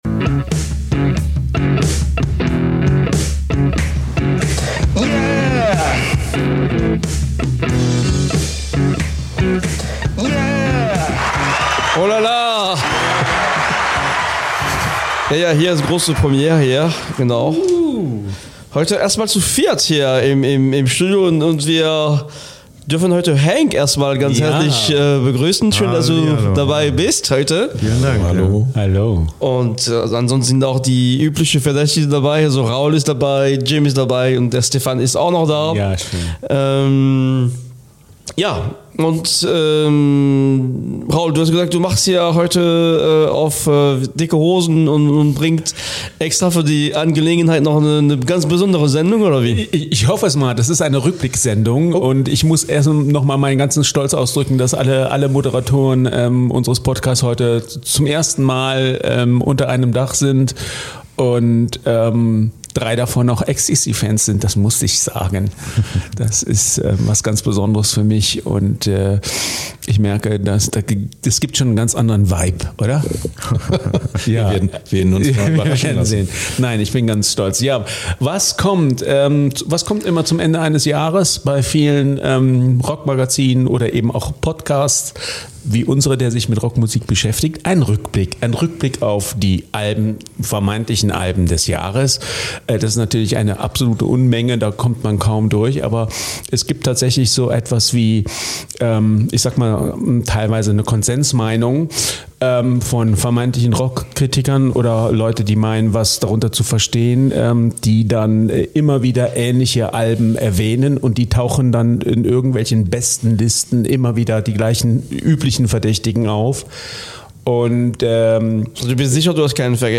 Letzte Episode #48 Die Alben des Jahres 2022? 22. Dezember 2022 Nächste Episode download Beschreibung Kapitel Teilen Abonnieren Zum Ende des Jahres endlich eine Premiere: Alle 4 Moderatoren unseres Podcasts zum ersten Mal in einer Episode! Und mit was für einem Thema: Wir besprechen die vermeintlichen Vinyl-Scheiben des Jahres.